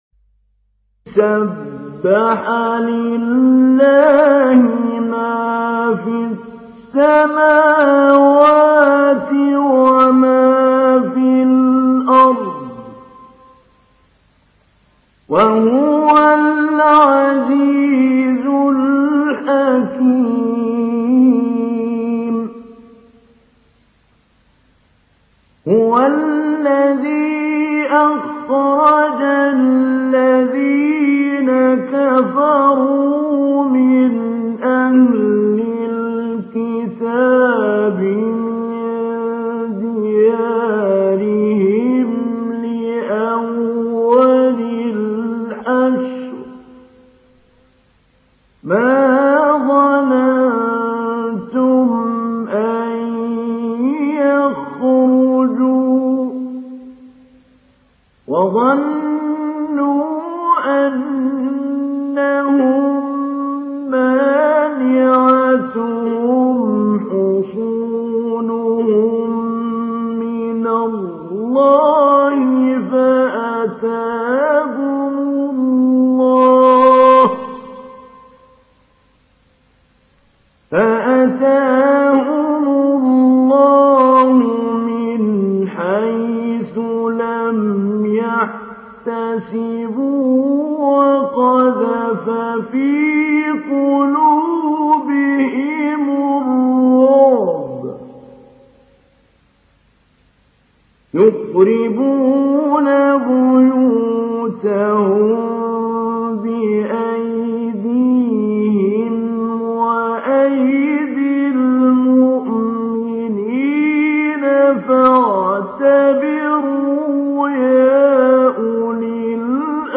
Surah Hasr MP3 in the Voice of Mahmoud Ali Albanna Mujawwad in Hafs Narration
Surah Hasr MP3 by Mahmoud Ali Albanna Mujawwad in Hafs An Asim narration.